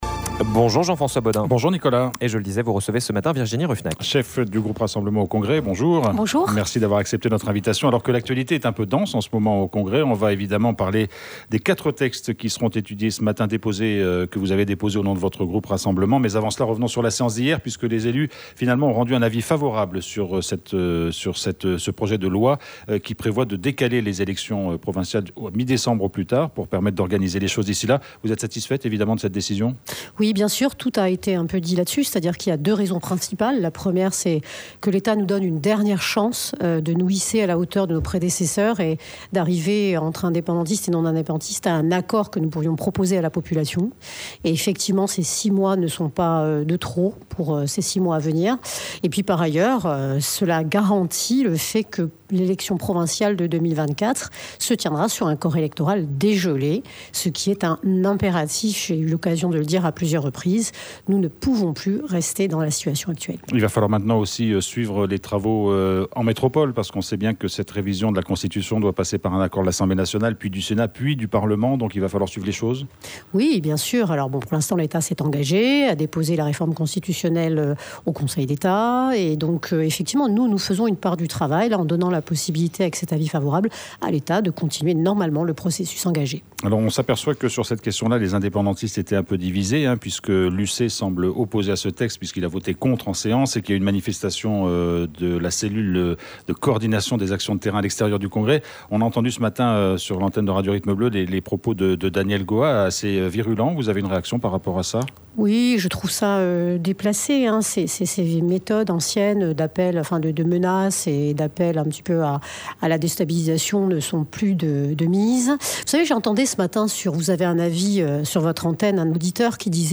L'INVITEE DU MATIN : VIRGINIE RUFFENACH